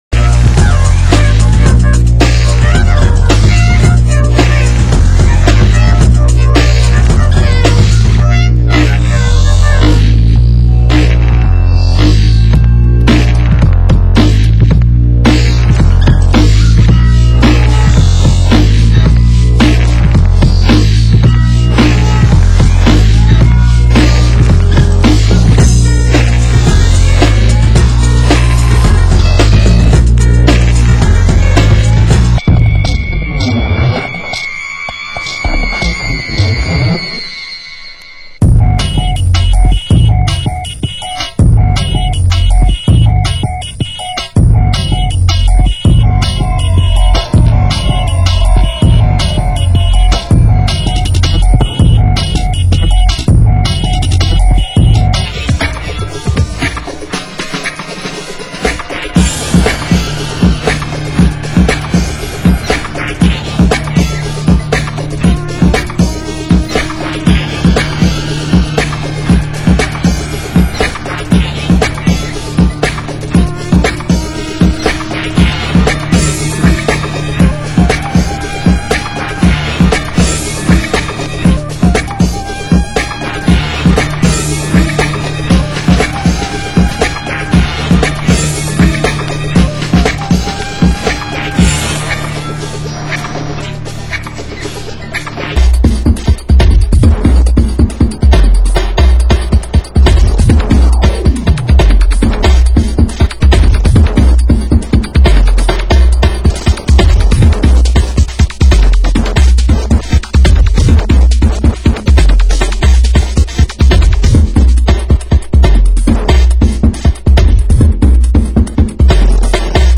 Genre: Techno
Genre: Break Beat